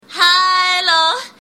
MP3 Custom Car Horns and Ringtones (Showing 20 Results)